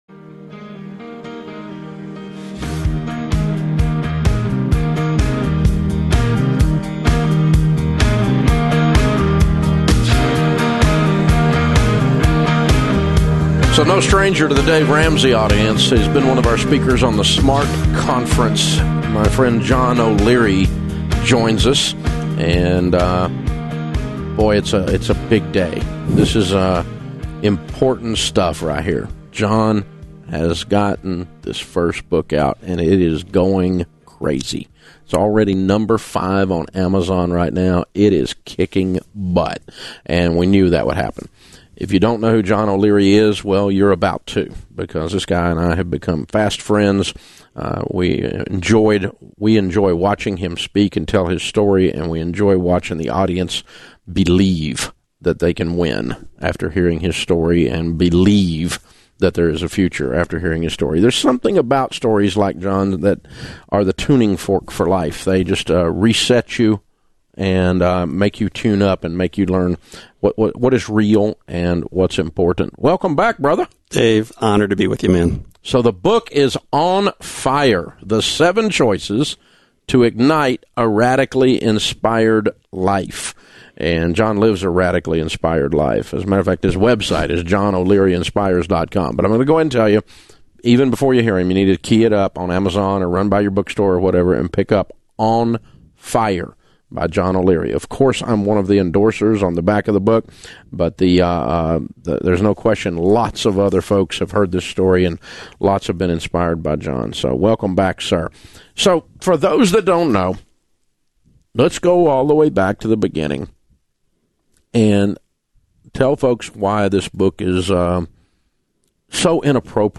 - Tonight I heard a fantastic Dave Ramsey interview with John O'Leary from March 22,2016. At 9, burns covered 100% of John's body, 87%, third degree, and he was not expected to live.